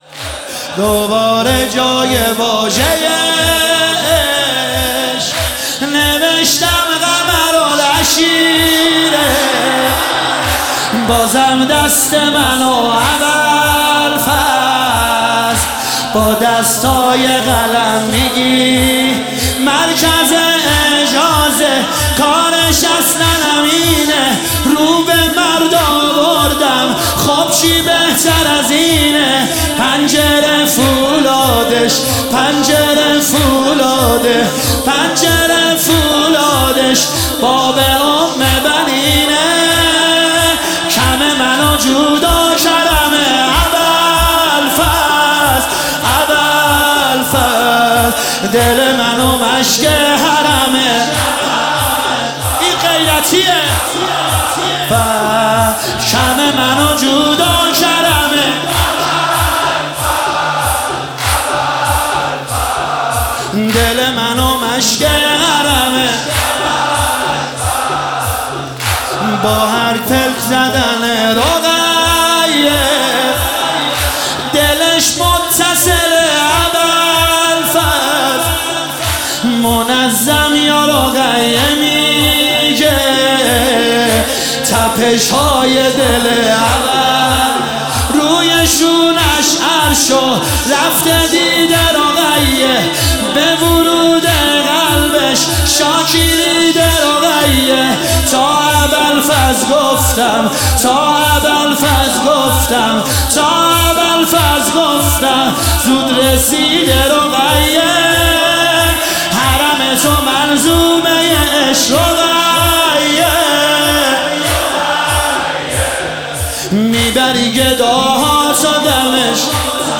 مداحی واحد
شب 23 ماه رمضان 1446